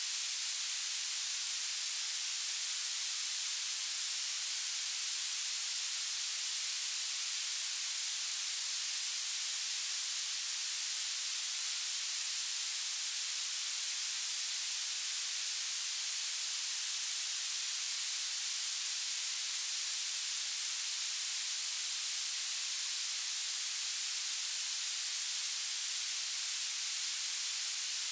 rain_level_1.ogg